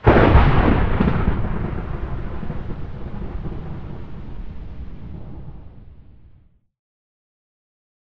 thunder3.ogg